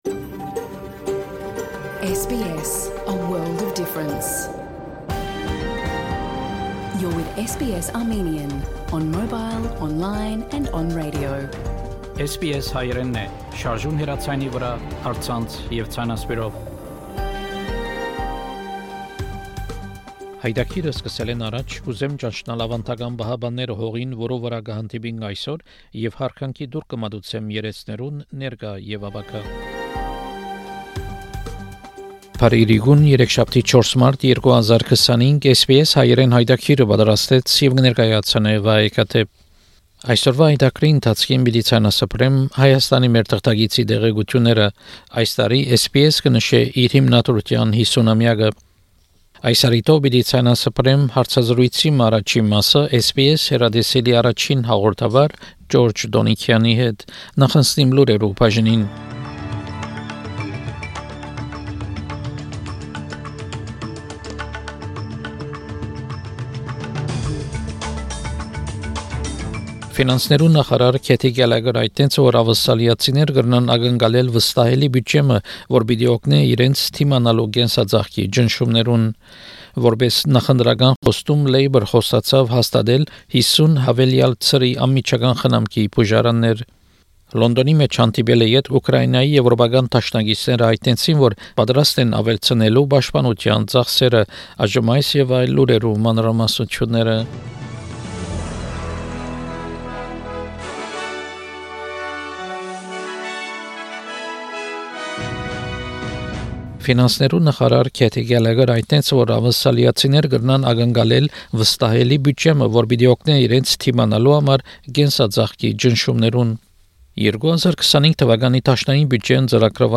SBS Armenian news bulletin from 4 March 2025 program.